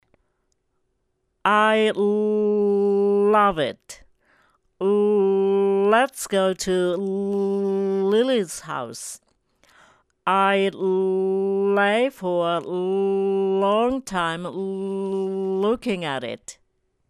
例文は同じですが、単語の初めのＬだけを長く発音して文を言う。